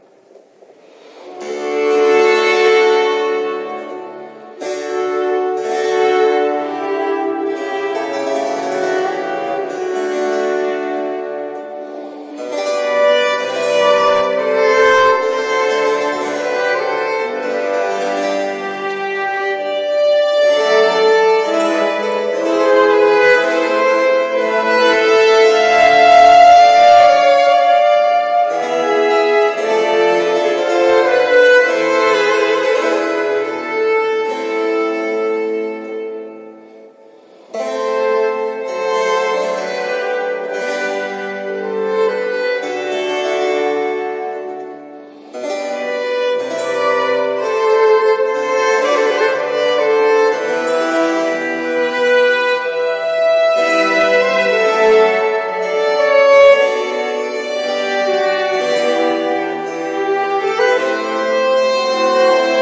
1. (Lent) 2. Gai 3. Large 4. Vif